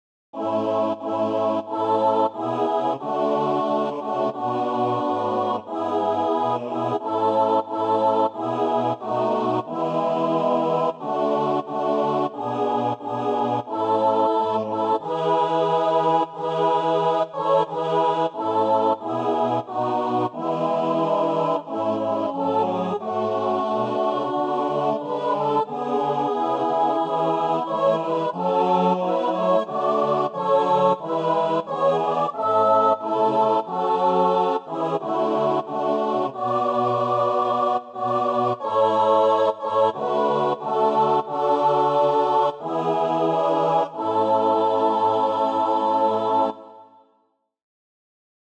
An original Christmas carol. The first verse is speaking to the shepherds who saw Christ at the time of his birth.
MAY BE SUNG A CAPPELLA, OR USING OPTIONAL ACCOMPANIMENT.
Voicing/Instrumentation: SATB , SATB quartet , Youth Choir Mixed Or Unison